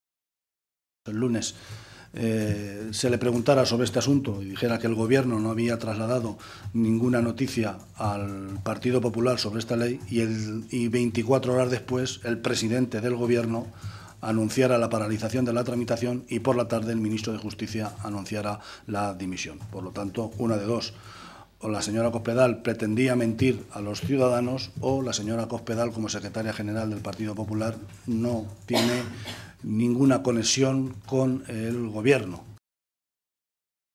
Guijarro se pronunciaba de esta manera esta mañana, en Toledo, en una comparecencia ante los medios de comunicación en la que avanzaba las intenciones de los socialistas en el contexto de la tramitación parlamentaria de la Ley de de Garantías y Derechos de las personas con discapacidad.